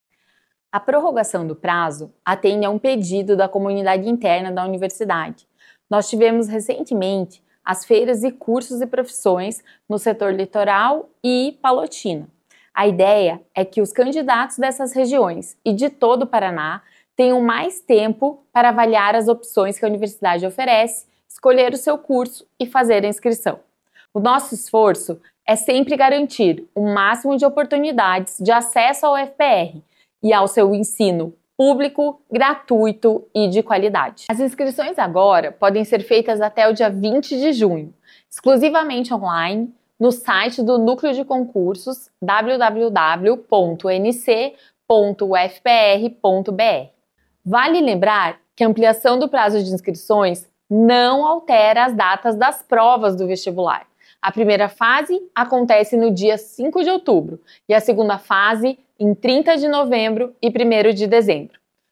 SONORA-PRORROGACAO-INSCRICOES-UFPR.mp3